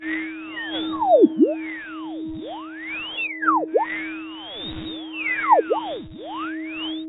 detectorFindsSomething.wav